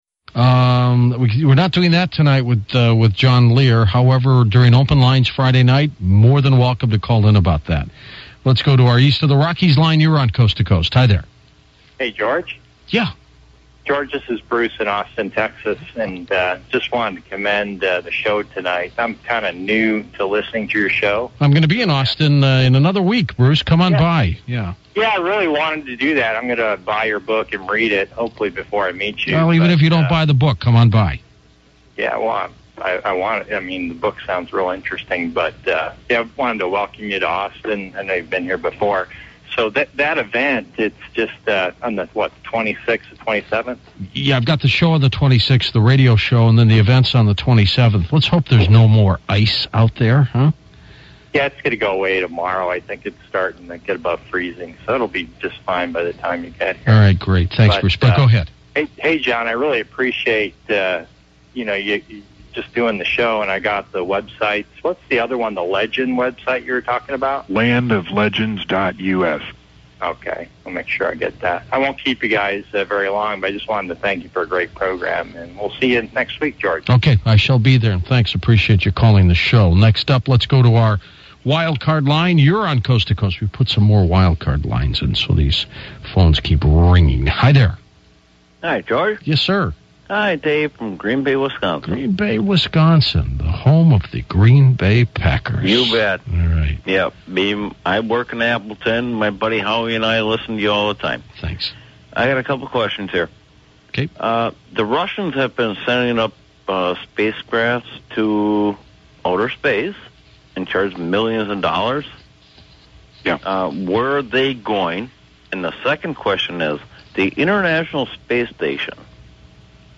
JohnLear 01-17-07 Interview part 7